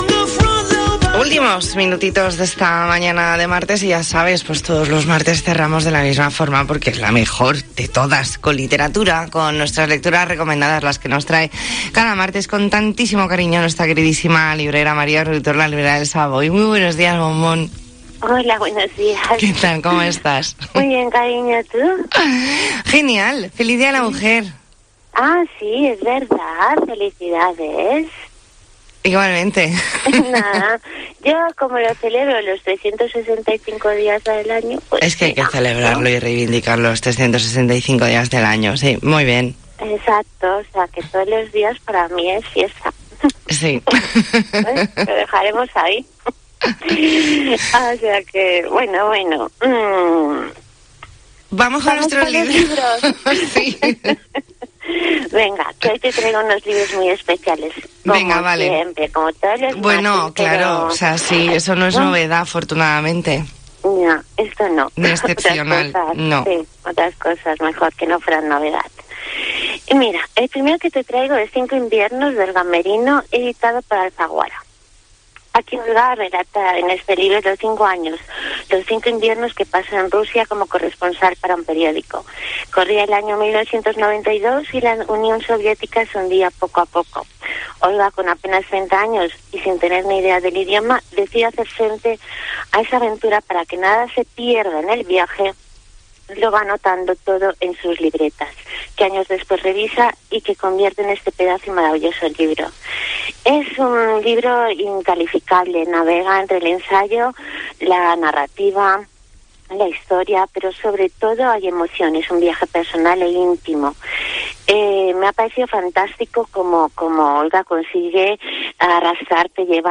Entrevista en 'La Mañana en COPE Más Mallorca', martes 8 de marzo de 2022.